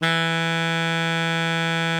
bari_sax_052.wav